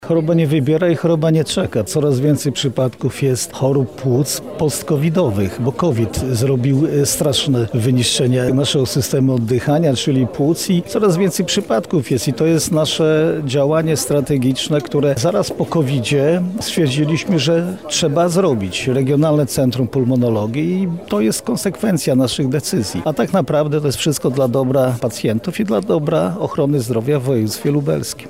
O motywacji powstania jednostki mówi Jarosław Stawiarski – Marszałek województwa lubelskiego.